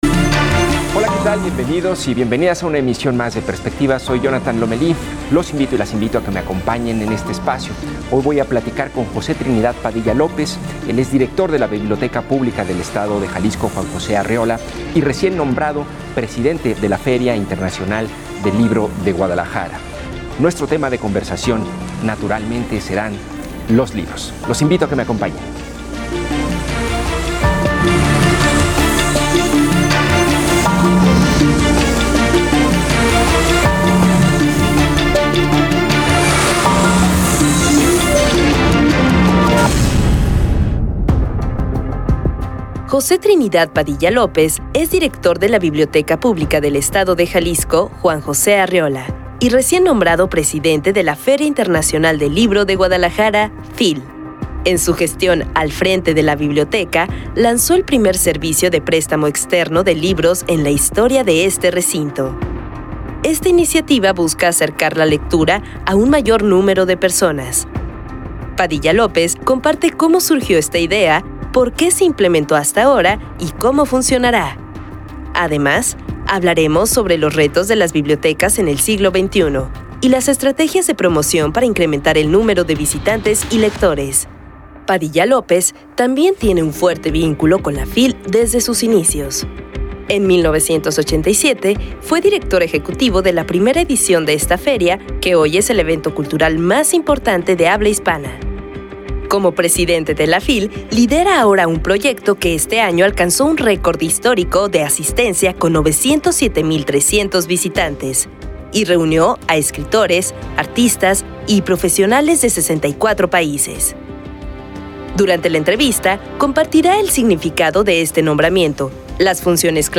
Entrevista con José Trinidad Padilla López, quien desde su cargo como director de la Biblioteca Pública del Estado de Jalisco ha impulsado el servicio de préstamo externo de libros.